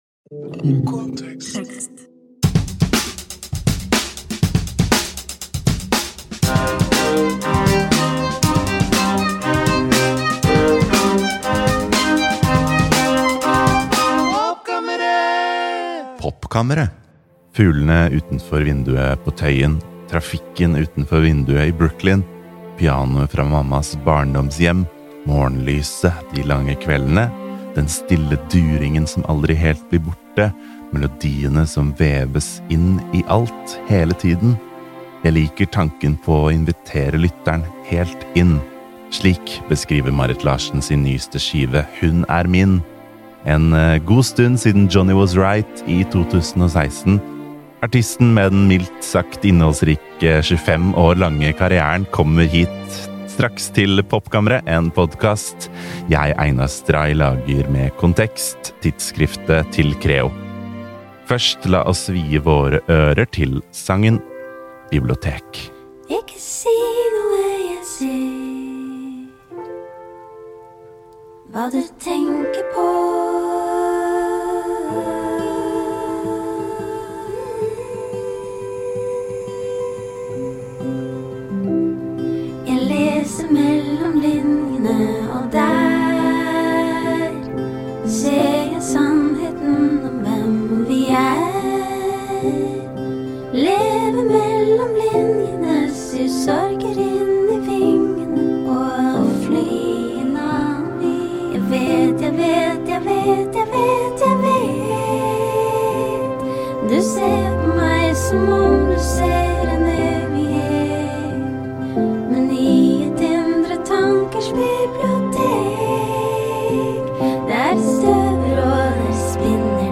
I Popkammeret sverger artisten at hun aldri noensinne skal bruke så lang tid på et prosjekt igjen. Marit viser fram demoer og skisser fra prosessen. Hun forteller om Brooklyn, å skrive på norsk, “the magic juice”, omsorgsstafett, karrierefeilskjær, kjærlighetsspråk, og mye mer.